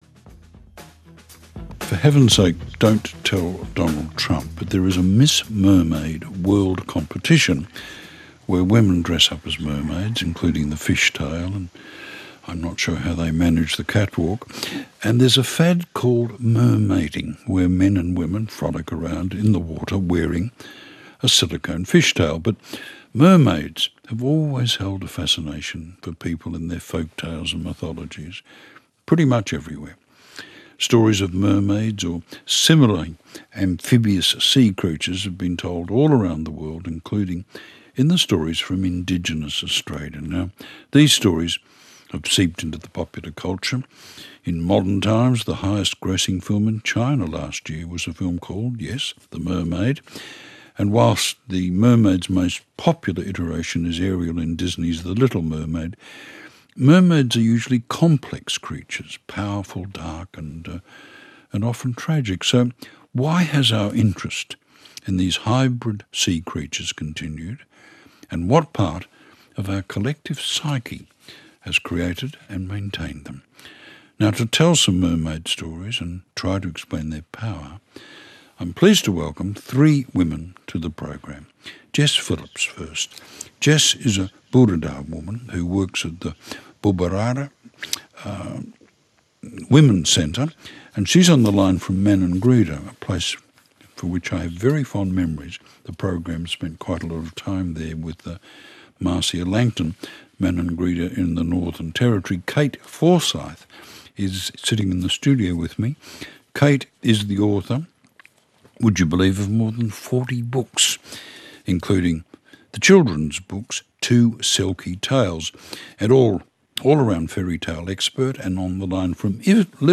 A couple of weeks ago I had the privilege of being part of a discussion about mermaids on Australia’s ABC Radio Network.